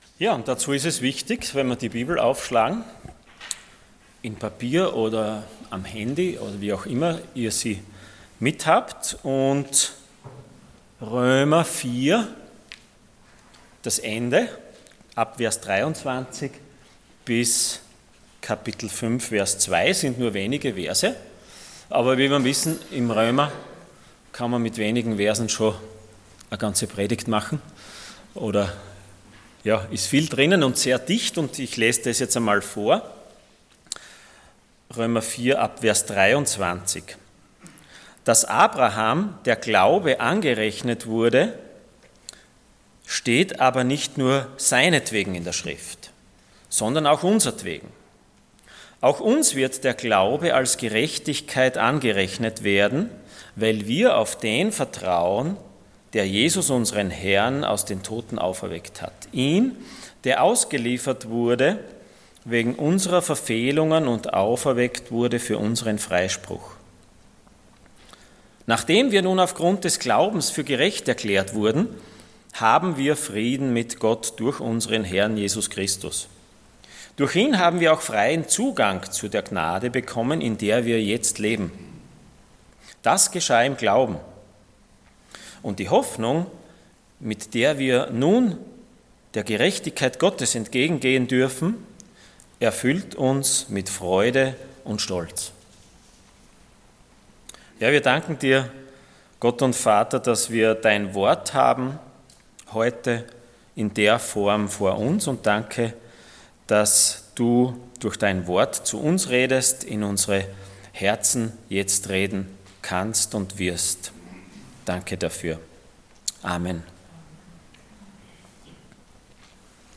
Passage: Romans 4:23-5:2 Dienstart: Sonntag Morgen